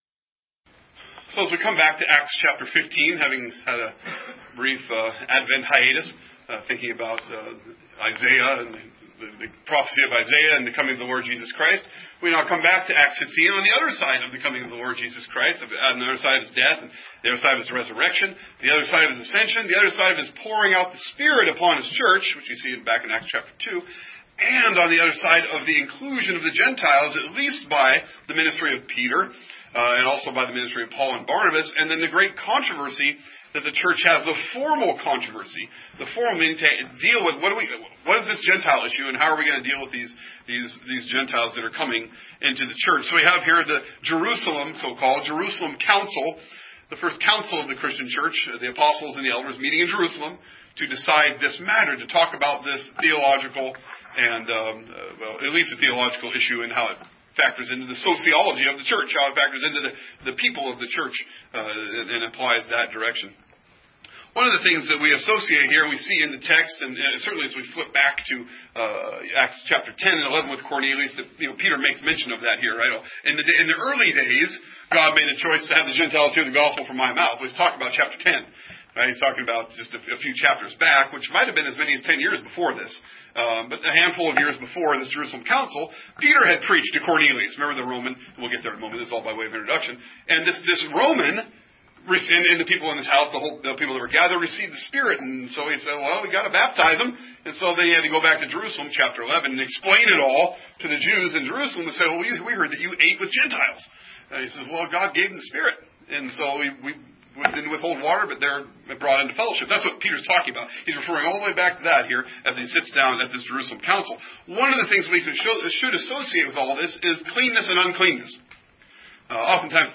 2016 Exposition On Acts Acts Acts Sunday Morning Worship This is a description that can be used to put your sermon outline or additional information like a scripture passage.